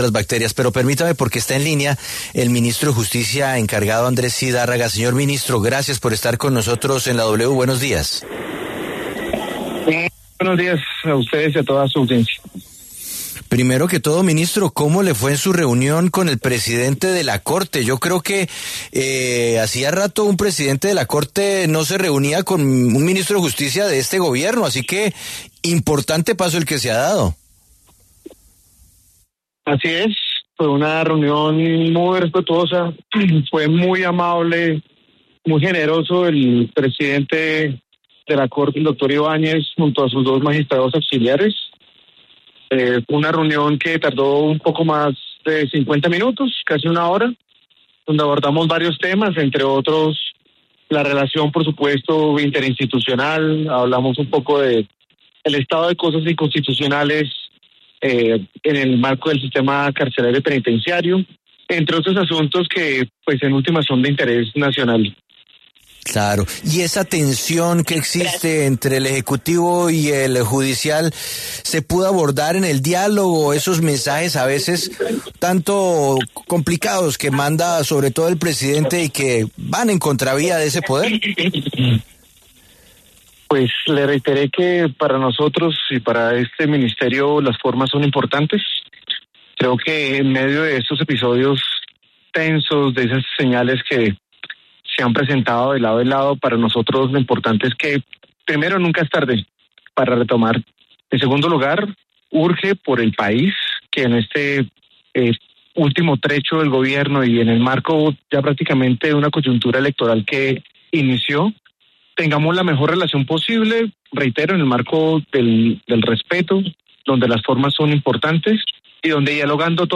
En diálogo con La W, el ministro de Justicia además señaló que no ha recibido respuestas tras sus denuncias hace unos meses contra el embajador (e) Óscar Muñoz, el mismo que baila con el prófugo.